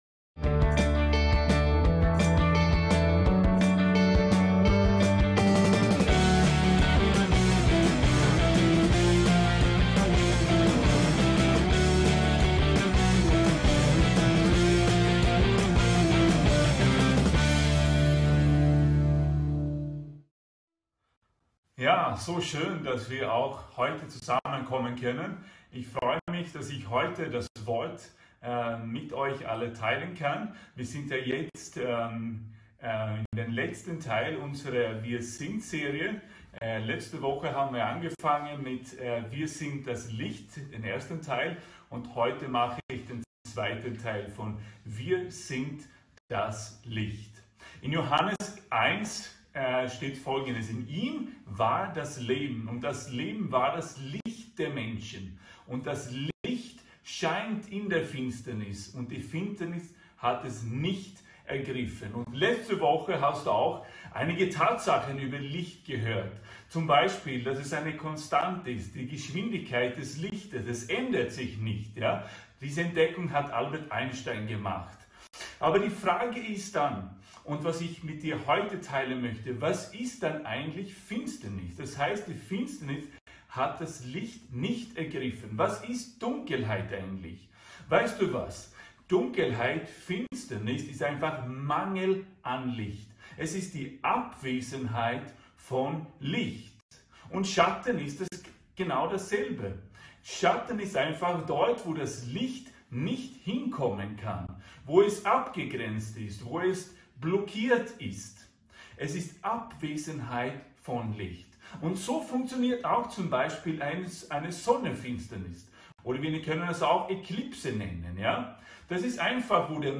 WIR SIND DAS LICHT 2 ~ VCC JesusZentrum Gottesdienste (audio) Podcast